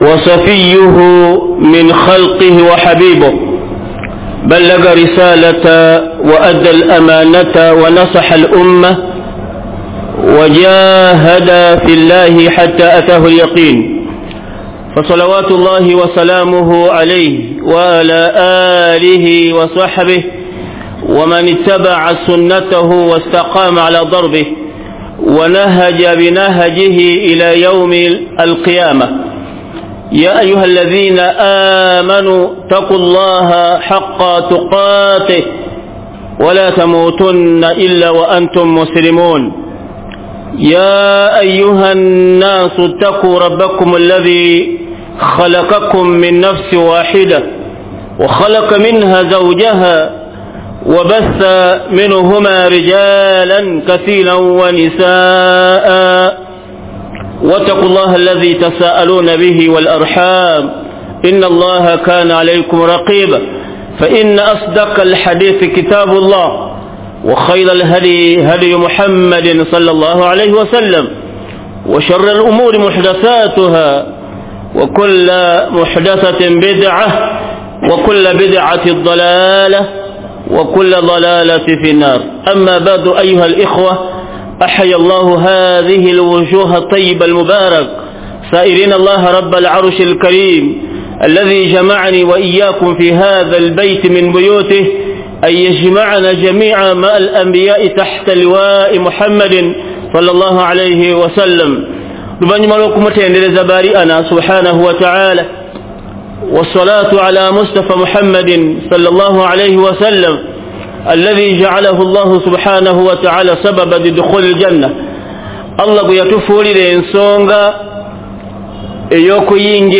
JUMMA KHUTUB
Masjid Nakasero Download Audio